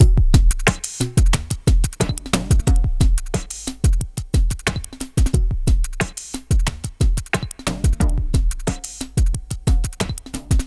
Downtempo 01.wav